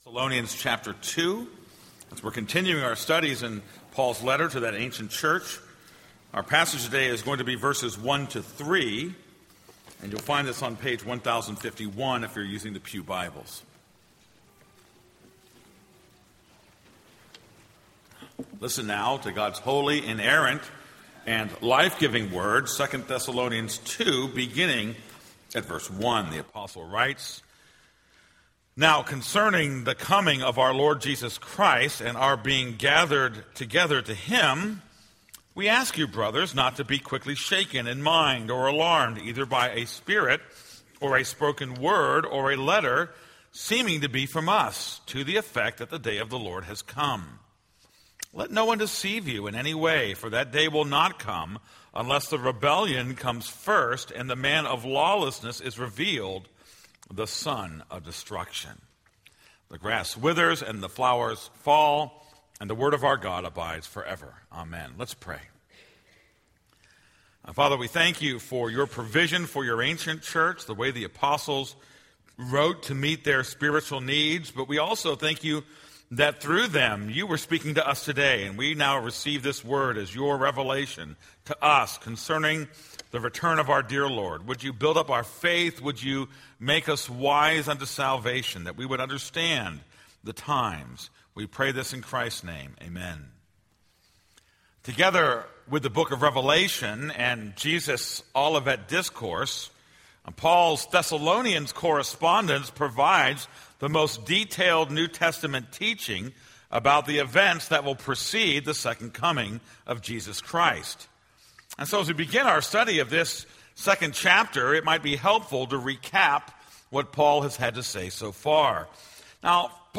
This is a sermon on 2 Thessalonians 2:1-4.